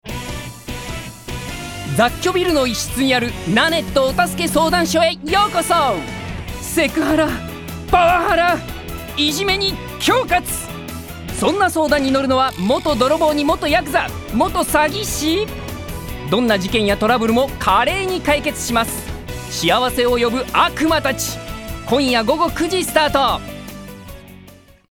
声を聴く Voice Sample
4.解決ドラマ番宣編